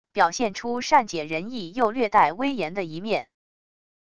表现出善解人意又略带威严的一面wav音频生成系统WAV Audio Player